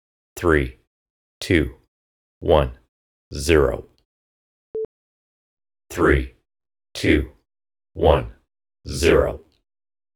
A. Chorus effect (before-after example attached)
I used this VST effect (plug-in) in Audacity 1.3.11 running on Windows Vista.